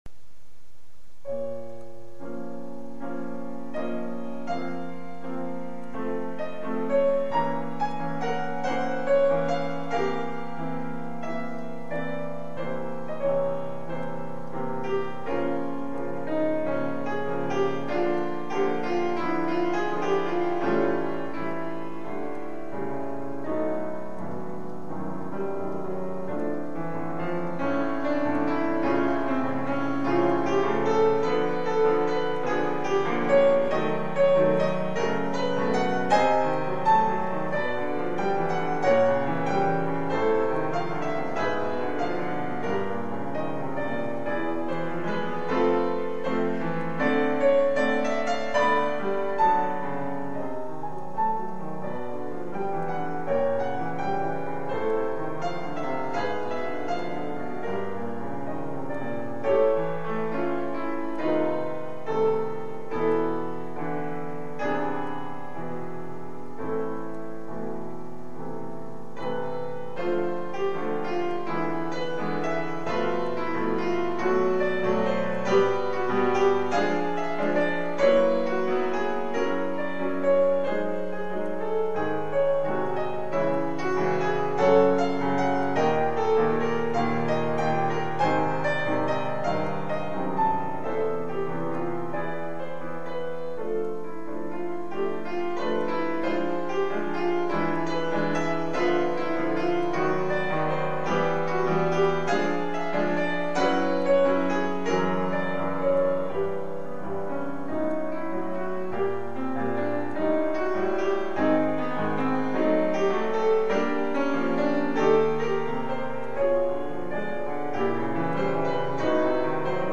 for solo piano
Brano di carattere meditativo ricco di procedimenti cromatici.
Perfomed in 2005 by the composer.